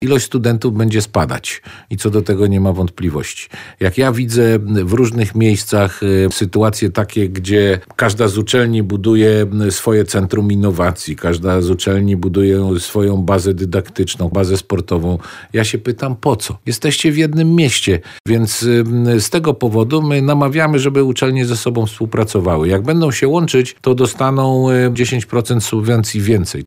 O łączeniu uczelni mówił gość porannej rozmowy Radia Lublin minister nauki i szkolnictwa wyższego Dariusz Wieczorek.